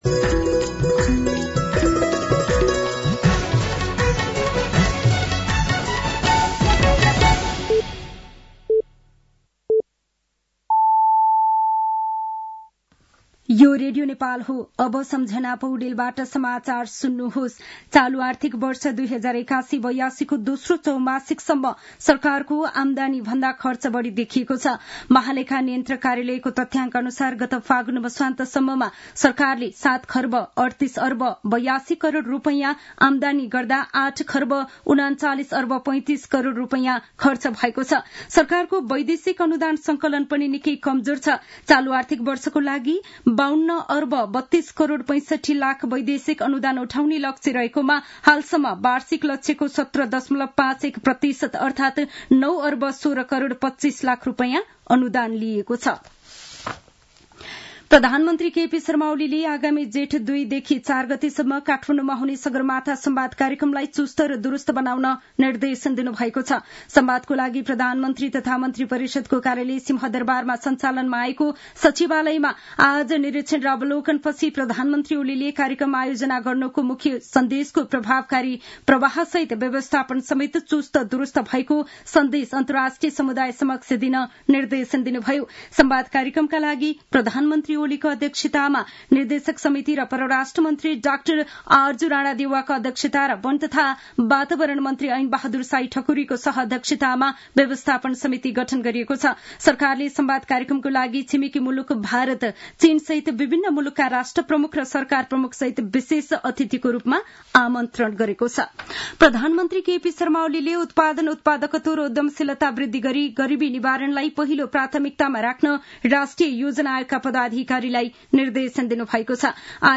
साँझ ५ बजेको नेपाली समाचार : १ चैत , २०८१